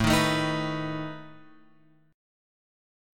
A Suspended 2nd Flat 5th